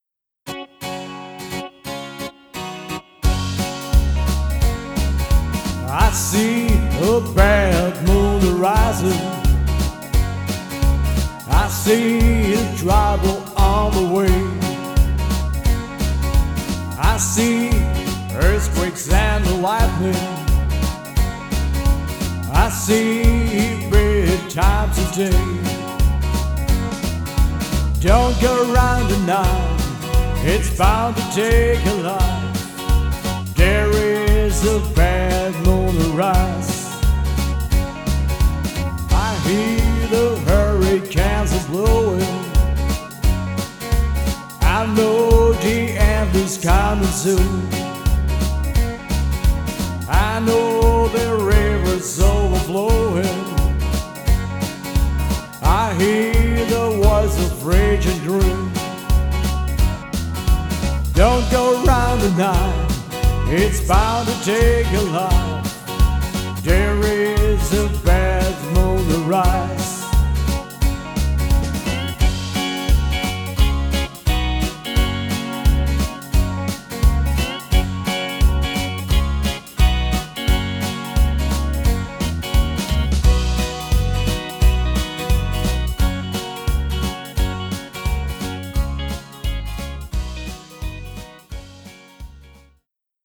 Rock`n Roll & Oldies